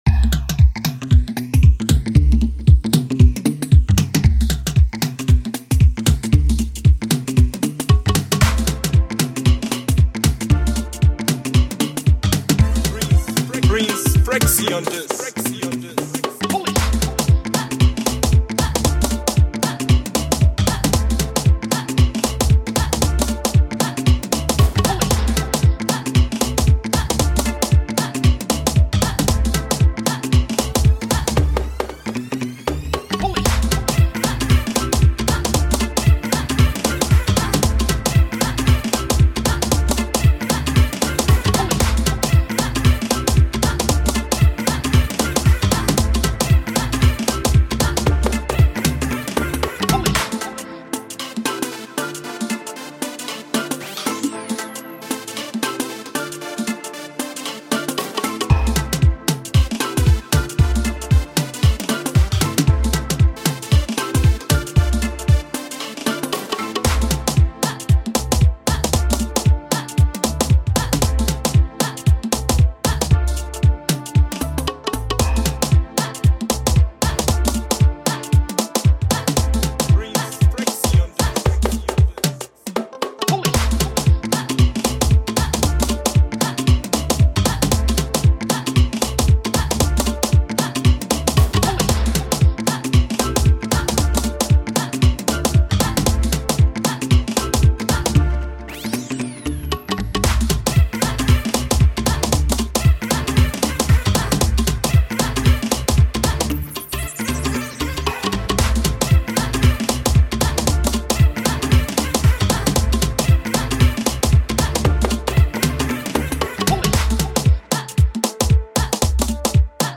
Free beat instrumental